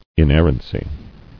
[in·er·ran·cy]
In*er"ran*cy , n. Exemption from error.